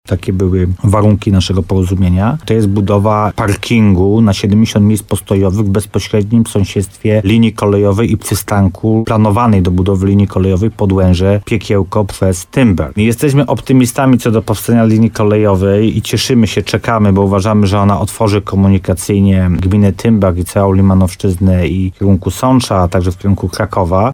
My jesteśmy optymistami co do powstania linii kolejowej, cieszymy się i czekamy, bo uważamy, że otworzy ona komunikacyjnie gminę Tymbark i całą limanowszczyznę w kierunku Nowego Sącza i Krakowa – mówi Paweł Ptaszek, wójt gminy Tymbark.